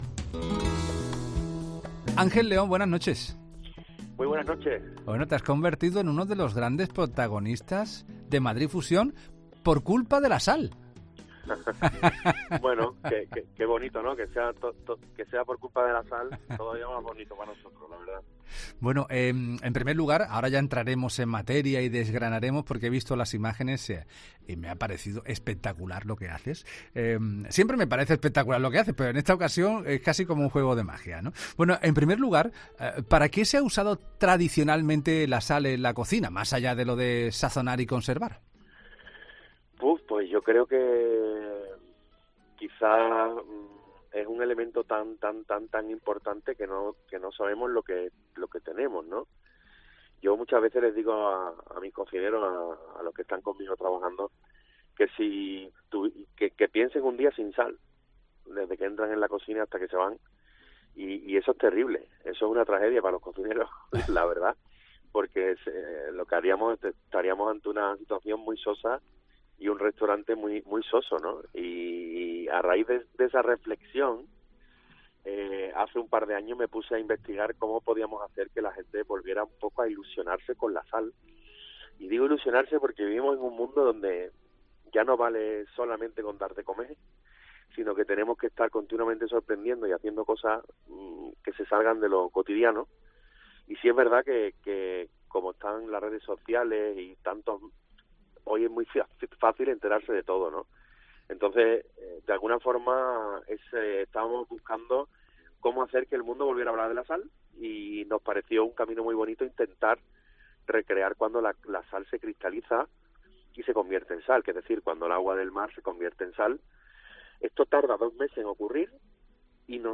El prestigioso chef español, Ángel León, cuenta las claves de su innovadora cocina en 'la Noche de COPE'